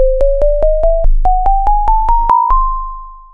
C5 chromatic scale
allnotesC5_NEW.wav